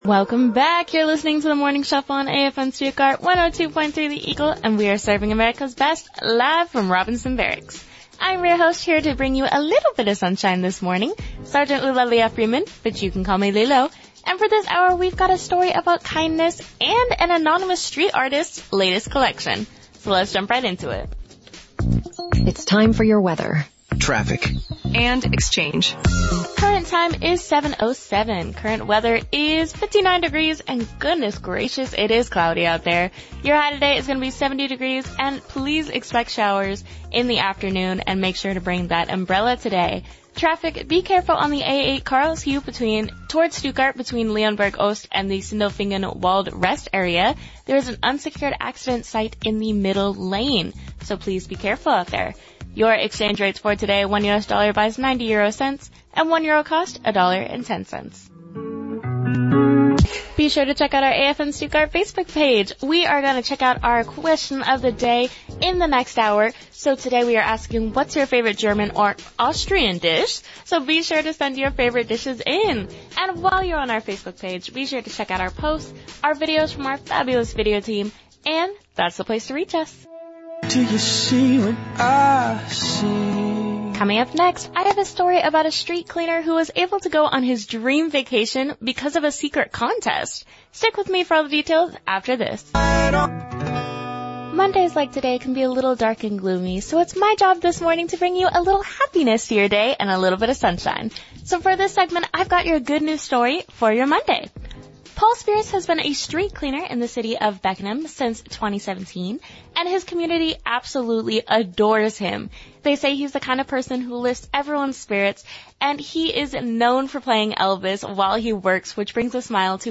AFN STUTTGART, Germany (September,9 2024) An hour of scoped radio cut highlights a DJ’s skills, show preparation, board fundamentals, and overall execution of their show. Music and advertisement were edited out to focus solely on the DJ’s performance.